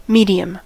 medium: Wikimedia Commons US English Pronunciations
En-us-medium.WAV